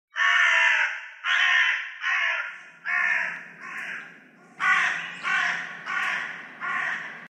Звуки ворон, воронов
На этой странице собраны разнообразные звуки ворон и воронов: от одиночных карканий до хоровых перекличек.